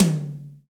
Index of /90_sSampleCDs/Roland L-CD701/TOM_Real Toms 1/TOM_Ac.Toms 1
TOM AMBGRT0D.wav